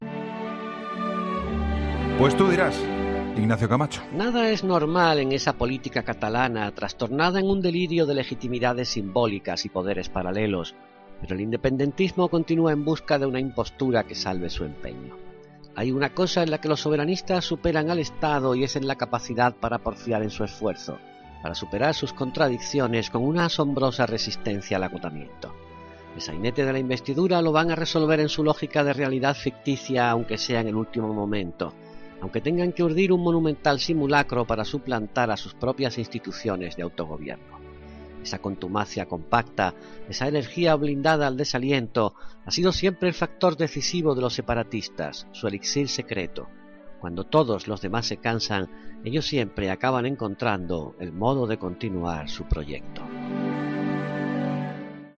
El comentario de Ignacio Camacho en 'La Linterna', este viernes sobre la reforma registrada por JxCat para intentar investir a Puigdemont: